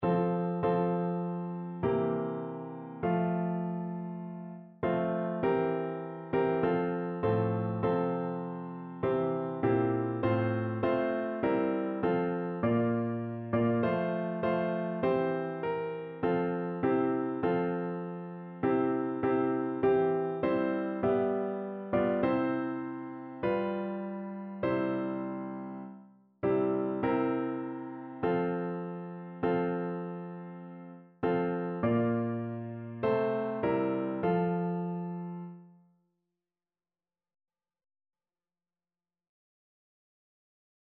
Notensatz 1 (4 Stimmen gemischt)